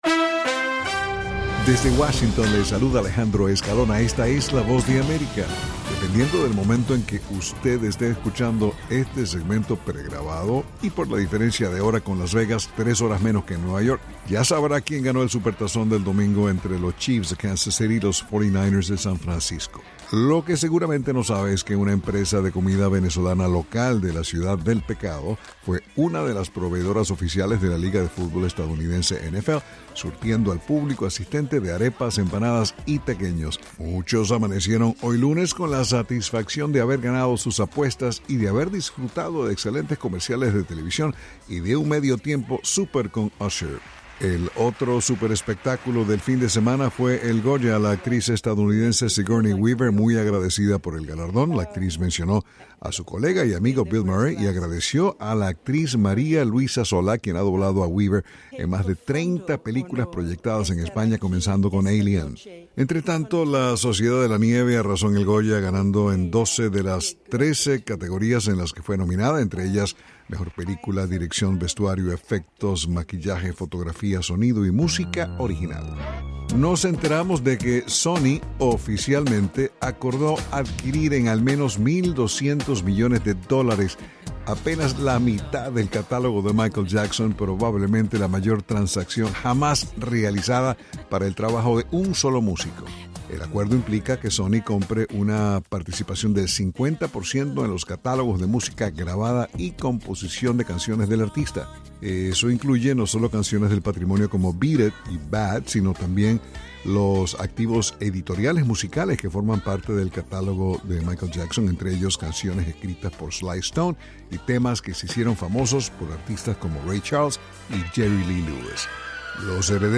las noticias del espectáculo